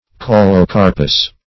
Search Result for " caulocarpous" : The Collaborative International Dictionary of English v.0.48: Caulocarpous \Cau`lo*car"pous\, a. [Gr.
caulocarpous.mp3